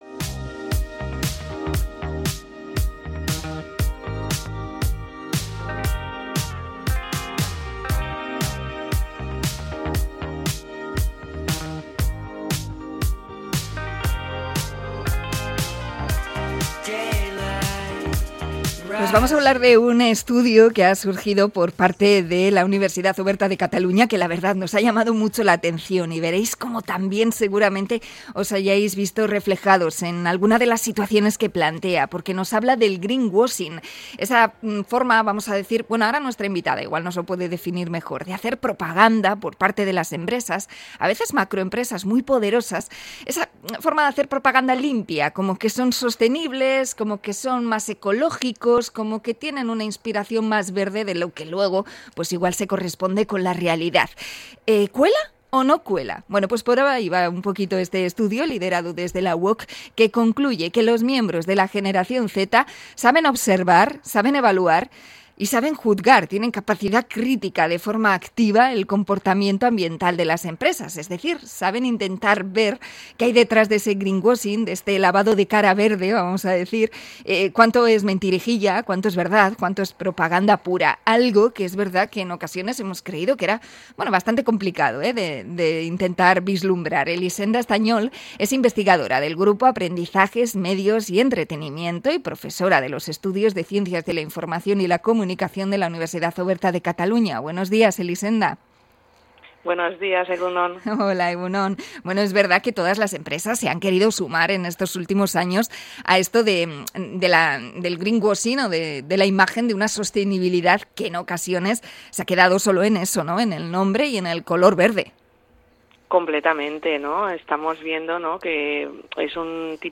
Entrevista a profesora de la UOC sobre el llamado 'greenwashing'